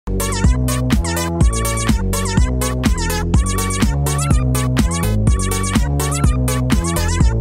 Meowmp3cutnet.mp3